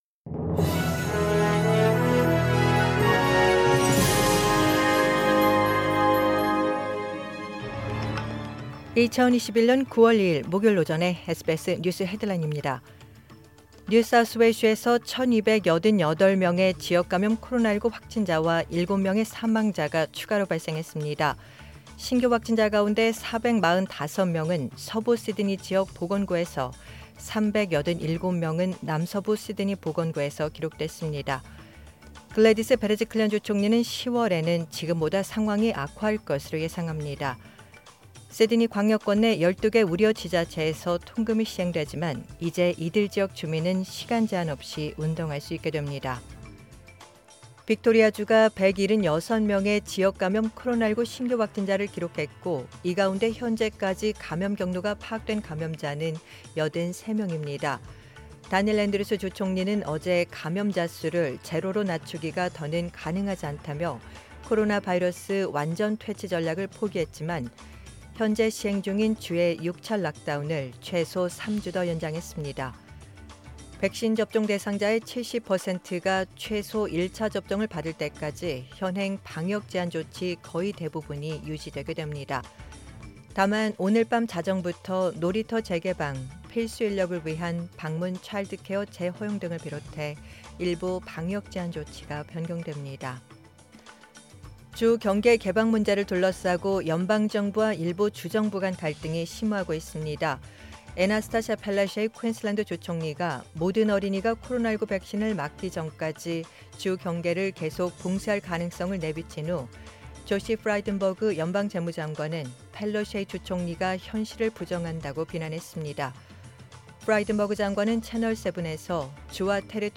2021년 9월 2일 목요일 오전의 SBS 뉴스 헤드라인입니다.